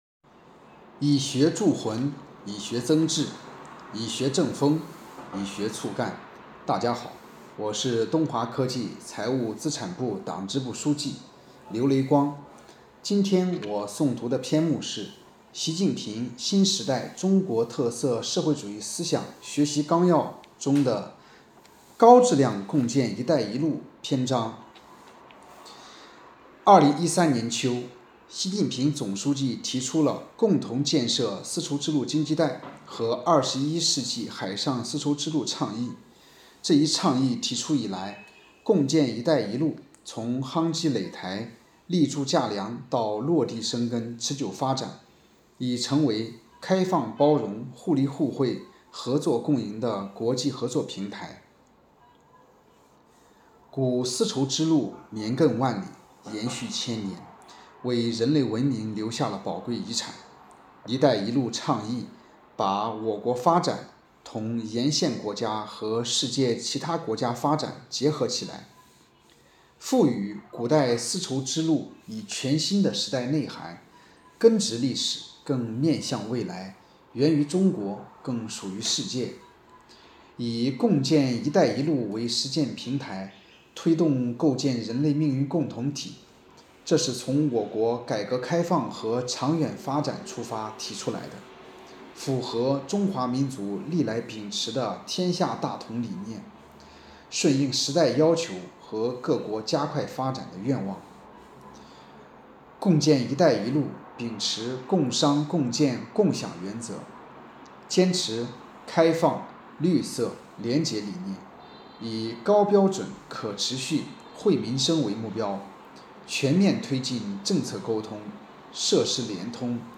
诵读人